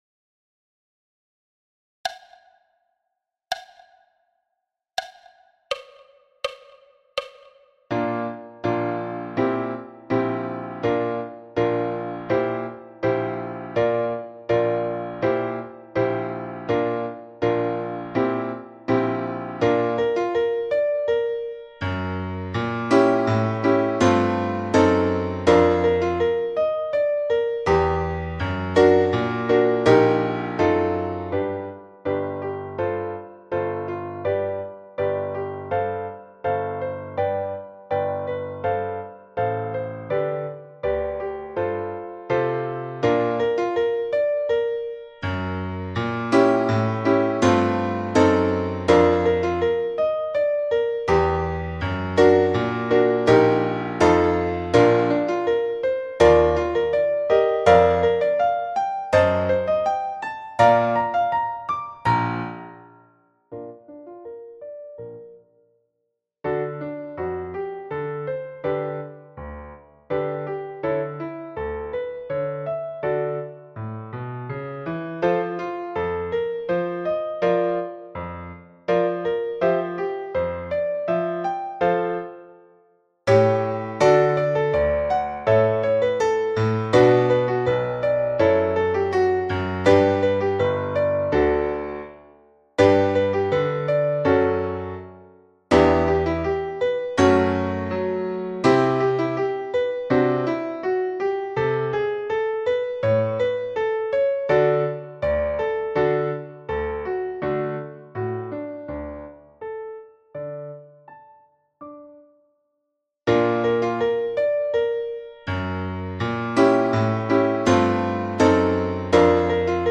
Parameters à 82 bpm